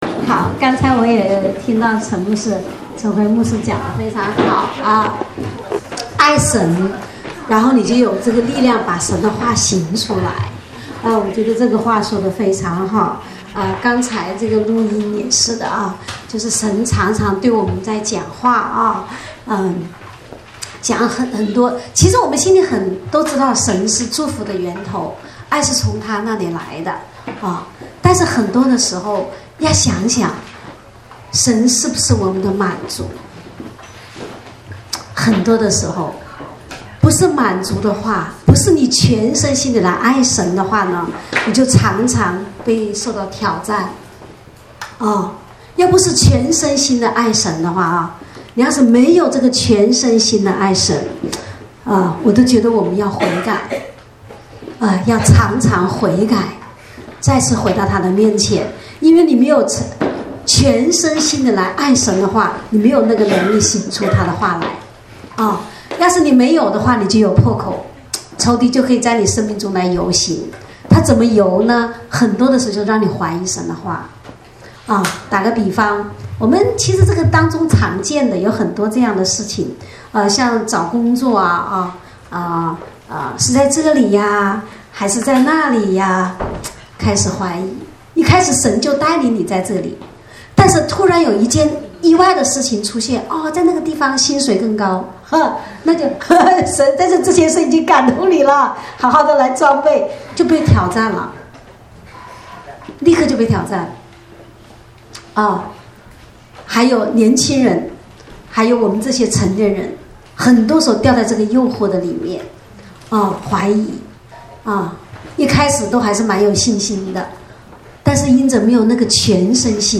正在播放：--主日恩膏聚会录音（2014-12-14）